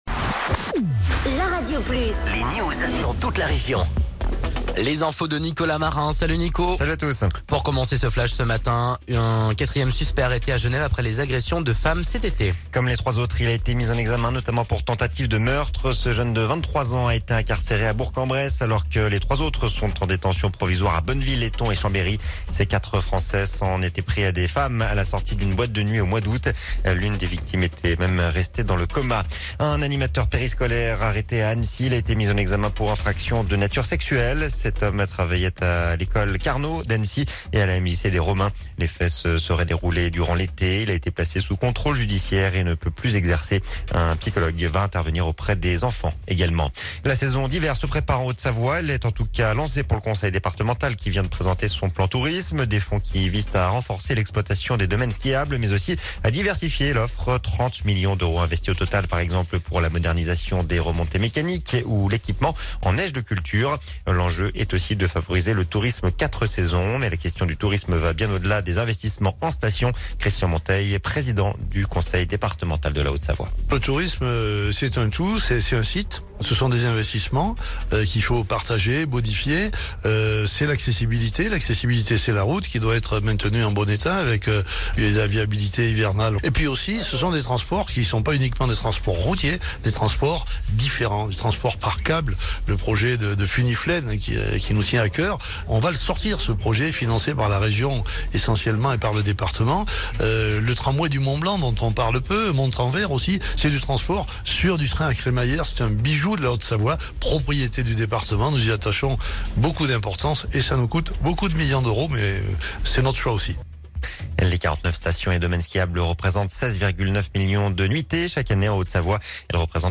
04.12.17 Flash Info 6H